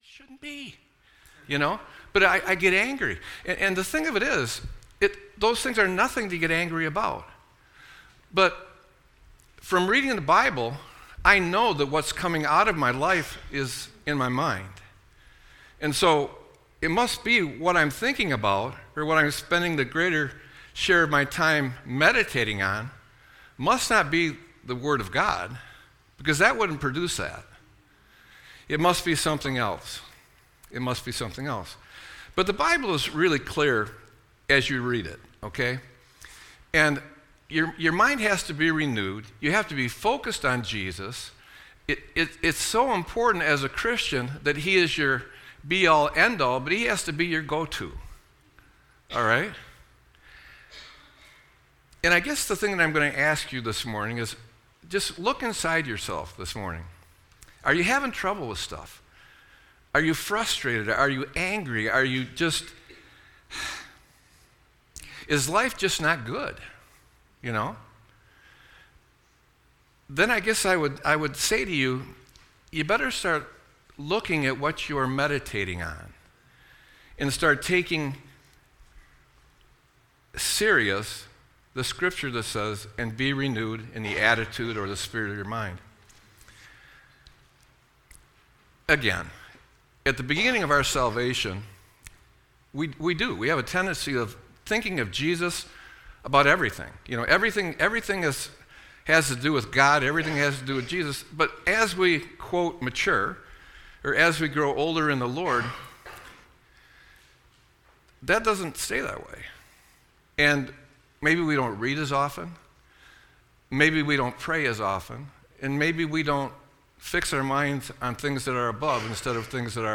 Sermon-7-20-25.mp3